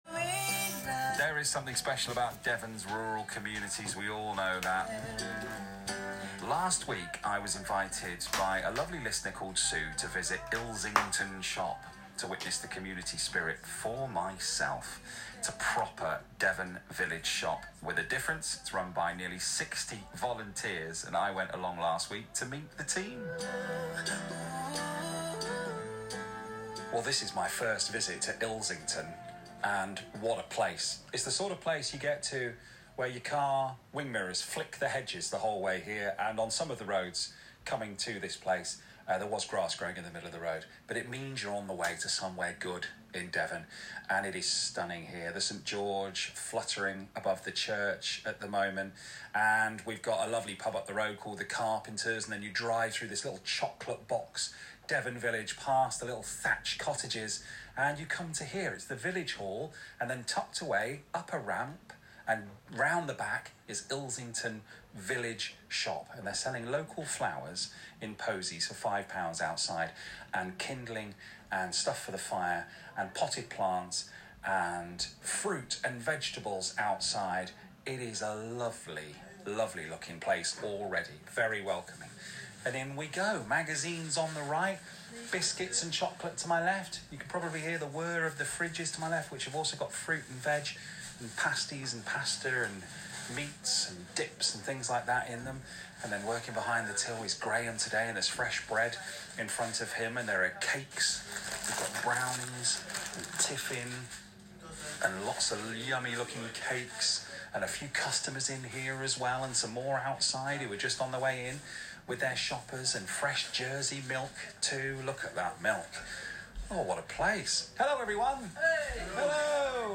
Ilsington Village Shop on BBC Radio Devon!